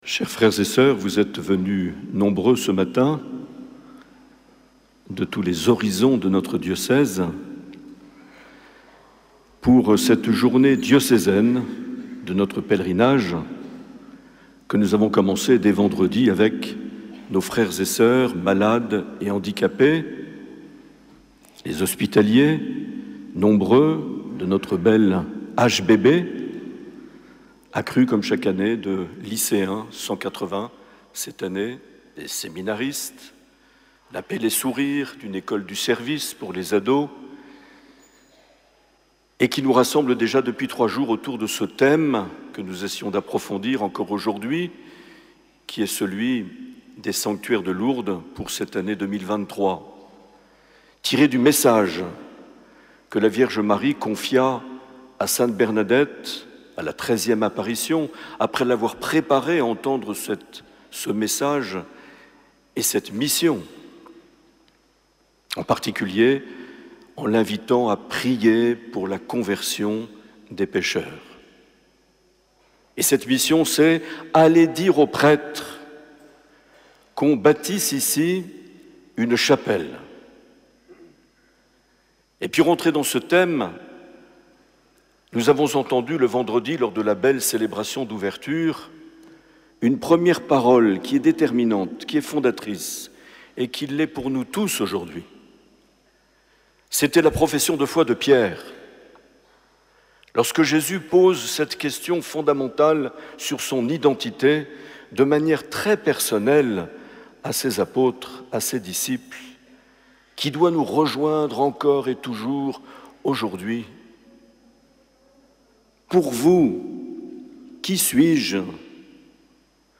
17 septembre 2023 - Lourdes - Pèlerinage diocésain - Messe diocésaine
Les Homélies
Une émission présentée par Monseigneur Marc Aillet